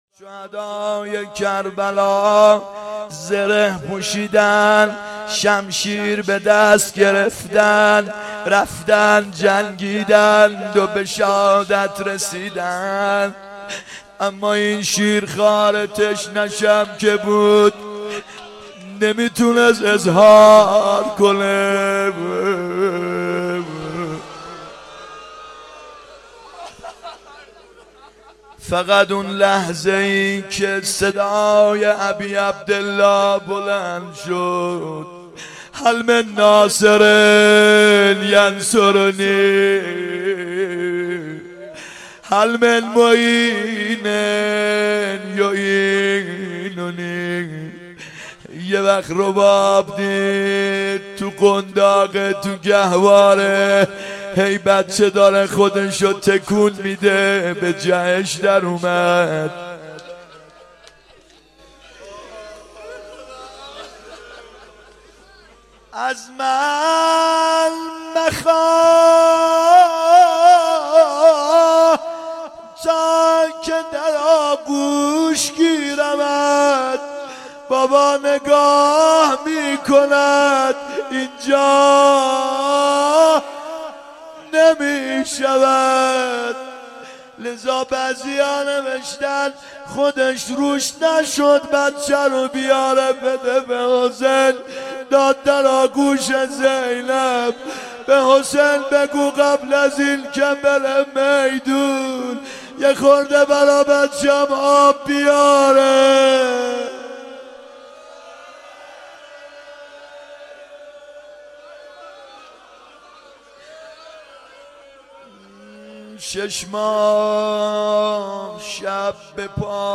محرم 91 شب هفتم روضه
محرم 91 ( هیأت یامهدی عج)